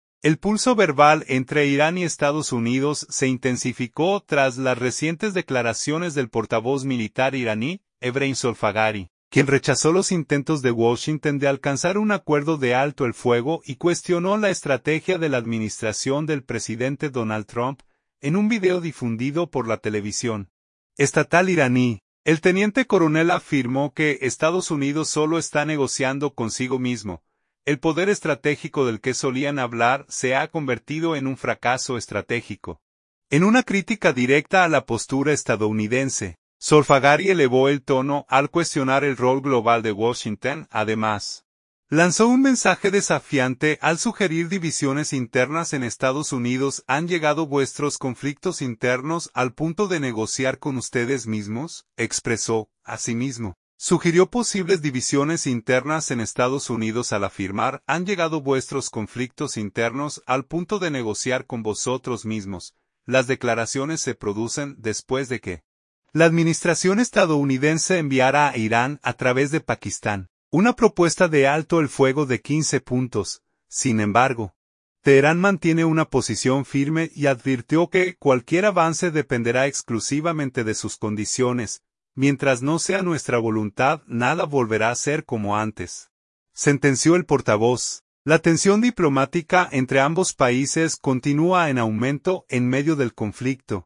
En un video difundido por la televisión estatal iraní, el teniente coronel afirmó que Estados Unidos “solo está negociando consigo mismo. El poder estratégico del que solían hablar se ha convertido en un fracaso estratégico”, en una crítica directa a la postura estadounidense.